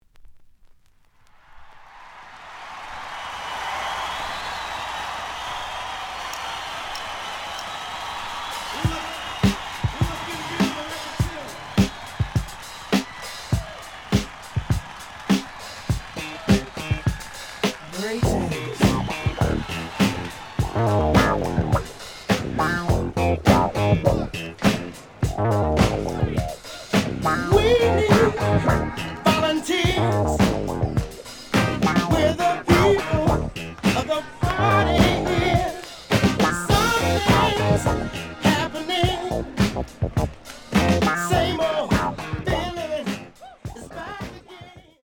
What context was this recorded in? The audio sample is recorded from the actual item. Edge warp. But doesn't affect playing. Plays good.)